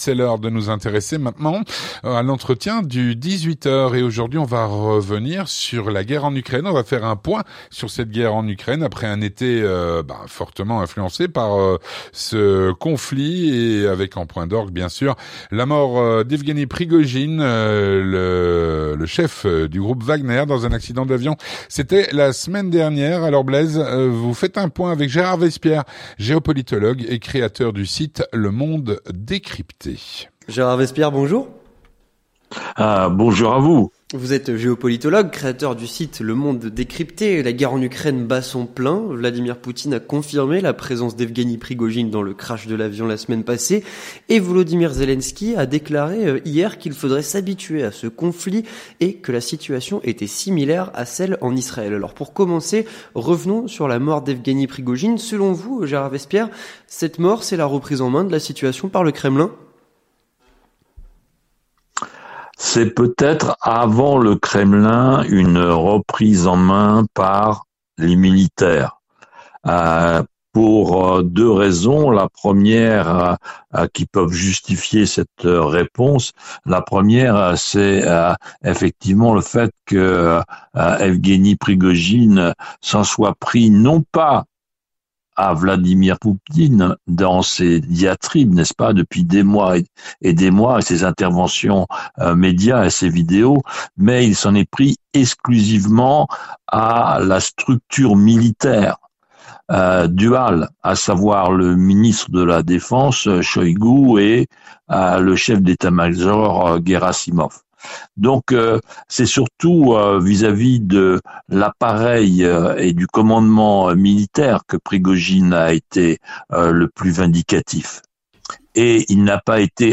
L'entretien du 18H - Un point sur la guerre en Ukraine.